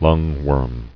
[lung·worm]